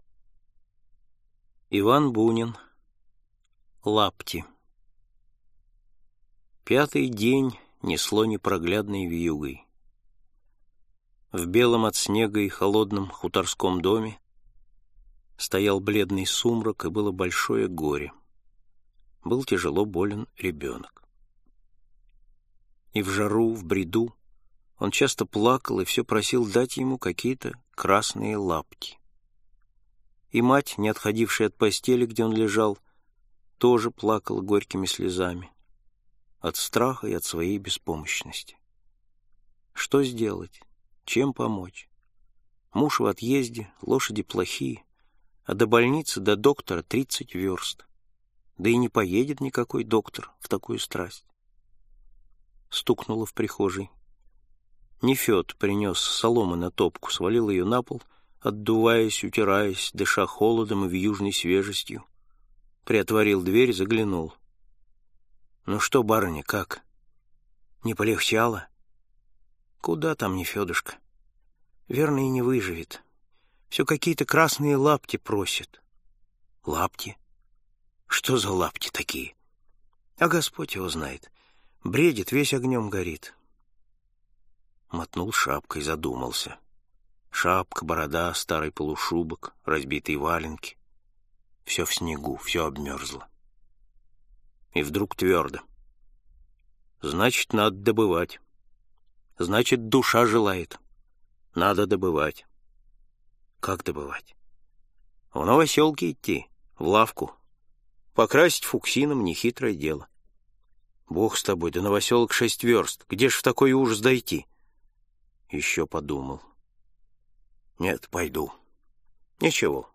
Аудиокнига Рассказы